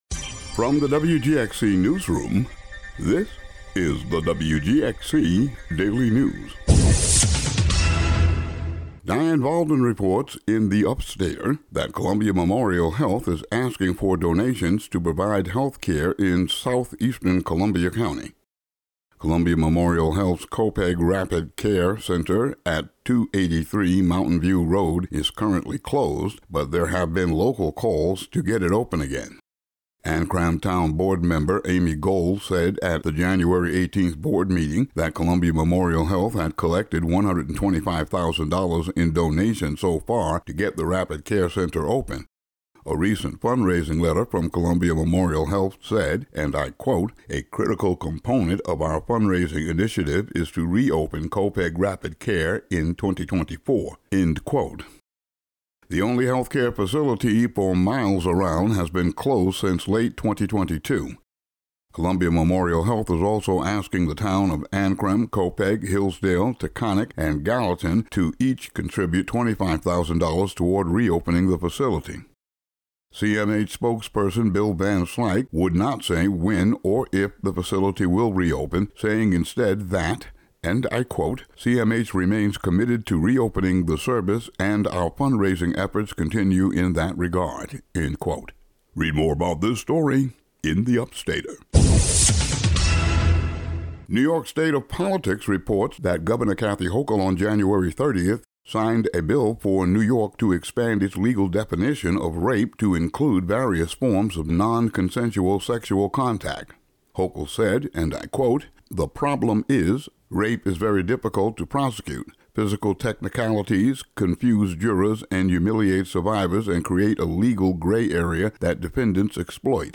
Today's audio daily news update.